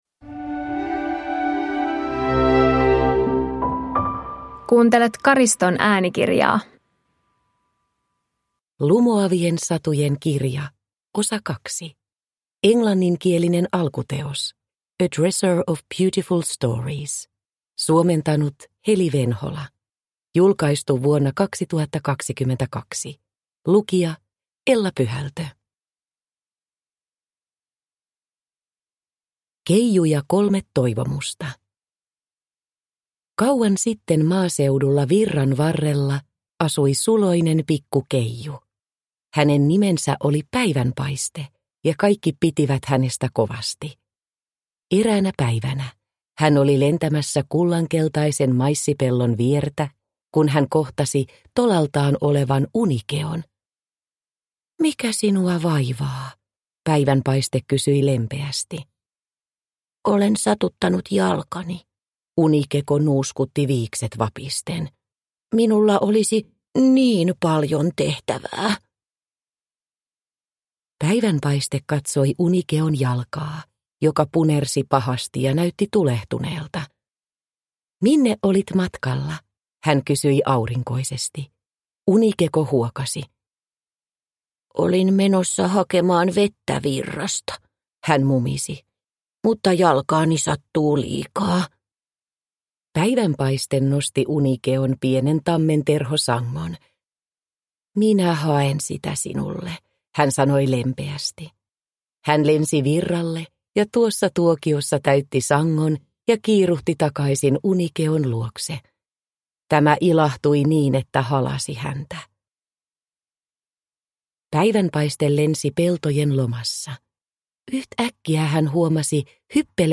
Lumoavien satujen kirja 2 – Ljudbok – Laddas ner